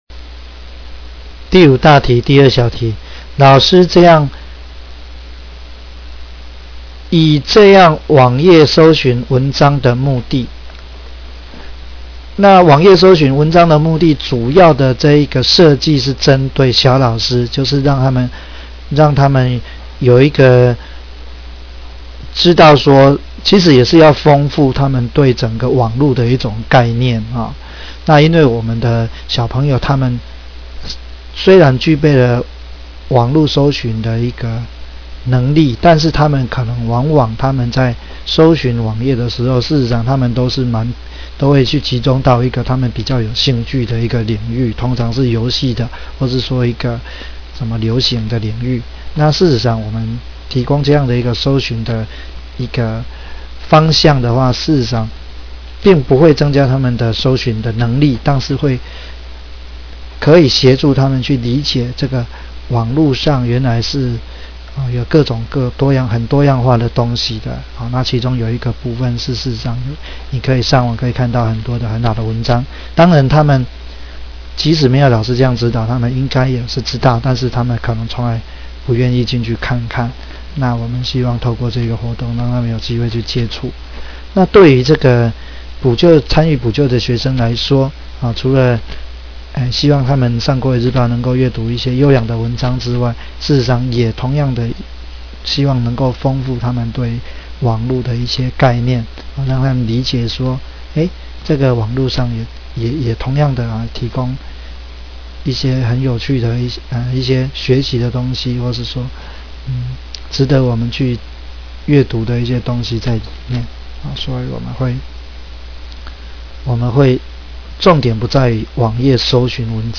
2-1 回覆(語音)第 02； 03； 04； 05-1； 05-2； 05-3題